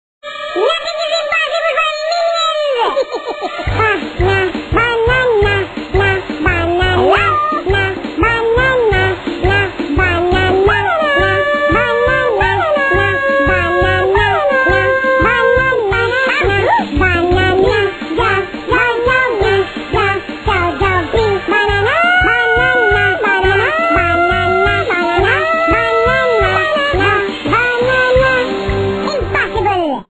หมวดหมู่: เสียงเรียกเข้า